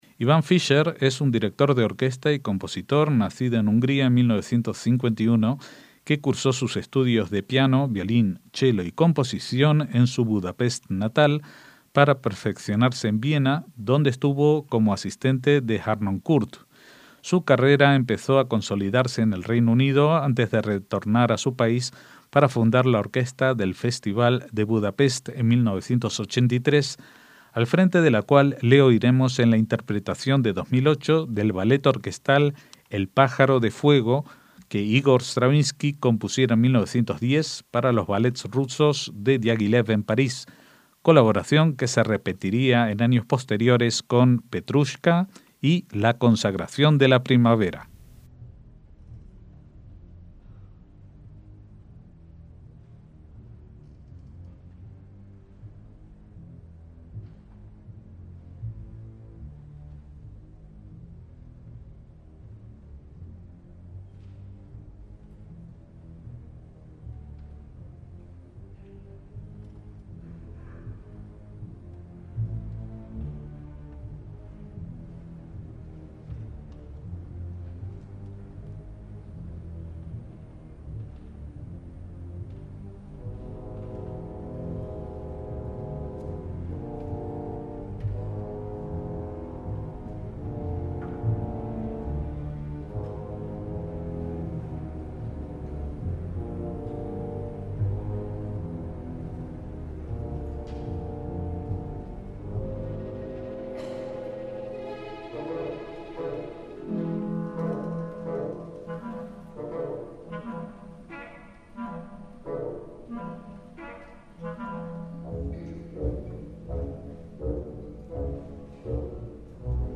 MÚSICA CLÁSICA
ballet orquestal